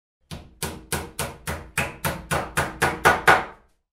Hammer Hitting Sound Effect Free Download
Hammer Hitting